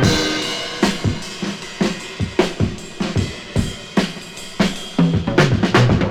• 79 Bpm Drum Loop D# Key.wav
Free drum beat - kick tuned to the D# note. Loudest frequency: 1268Hz
79-bpm-drum-loop-d-sharp-key-18D.wav